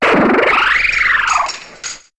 Cri de Cliticlic dans Pokémon HOME.